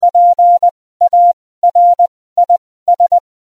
Betriebsart CW (Morsen)
CW-Audiobeispiel des Worts "PARIS" im Thempo 20/15 WPM (Farnworth):
cw-paris-20_15.mp3